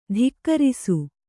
♪ dhikkarisu